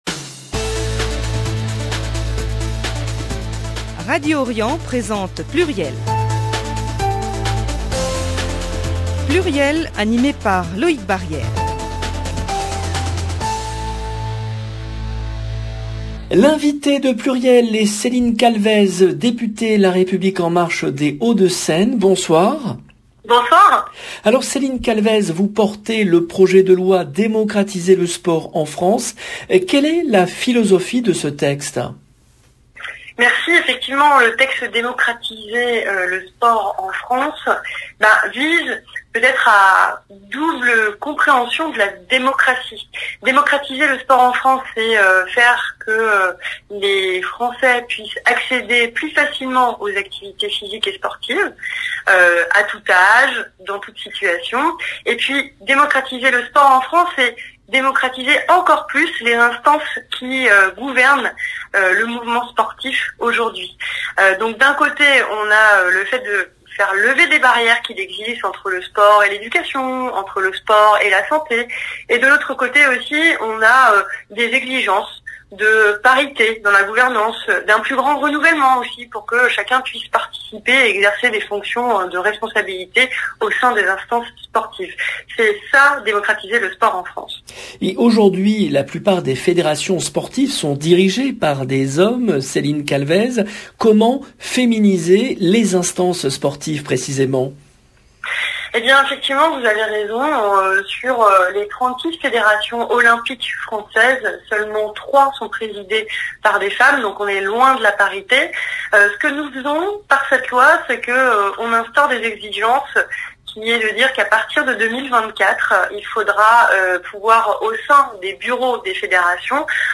Céline Calvez, députée LREM des Hauts-de-Seine